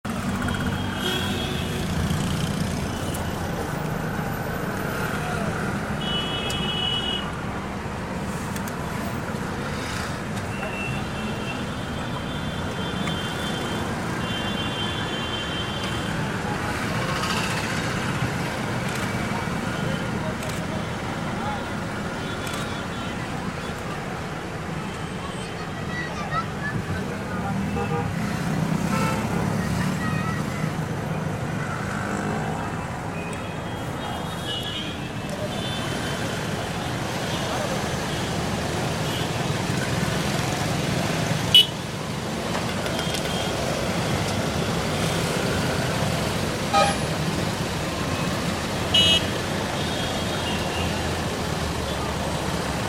Ambiance de rue à Pune
bruits de la rue à Pune